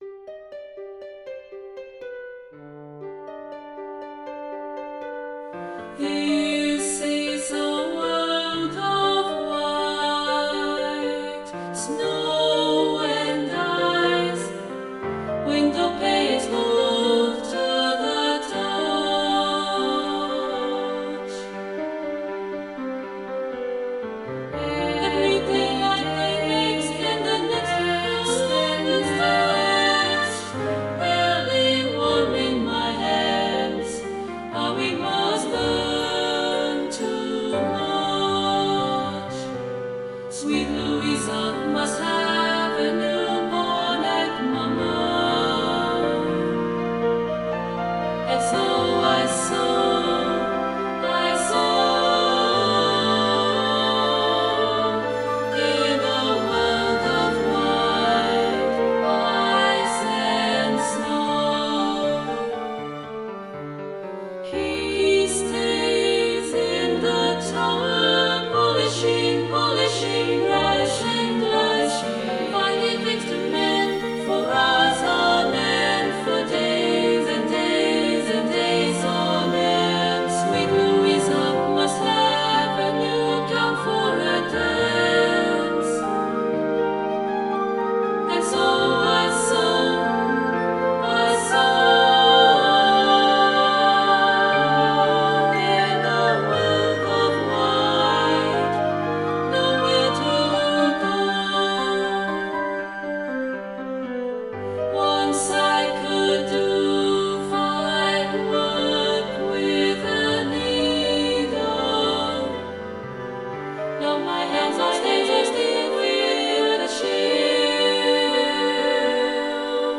This haunting piece for SSAA and piano or chamber orchestra
SSAA & Piano, Full Score & Chamber Orchestra Parts